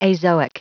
Prononciation du mot azoic en anglais (fichier audio)
Prononciation du mot : azoic